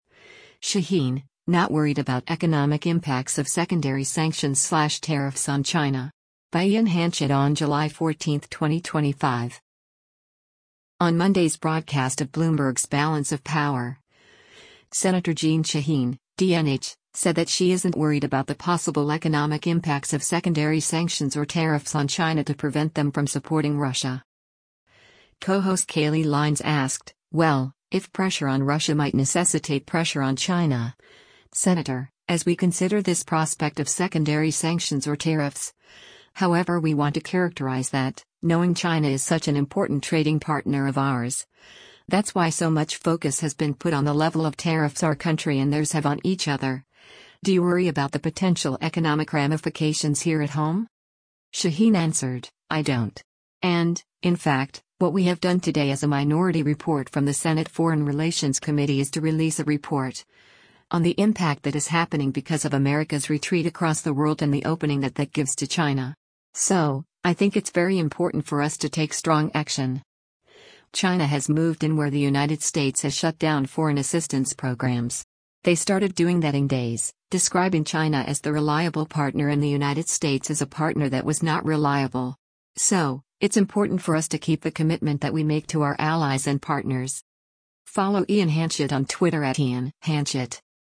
On Monday’s broadcast of Bloomberg’s “Balance of Power,” Sen. Jeanne Shaheen (D-NH) said that she isn’t worried about the possible economic impacts of secondary sanctions or tariffs on China to prevent them from supporting Russia.